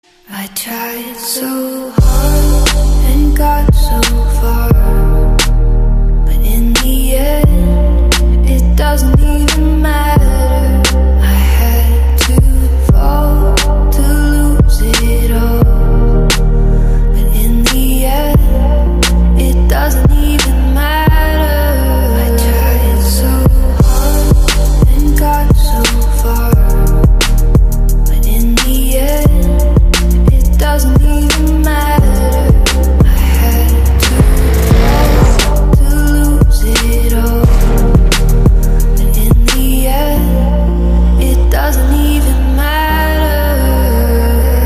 Медленная